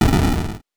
explosion_17.wav